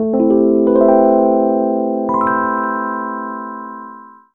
04 Cheesy Poops 165 Dmin.wav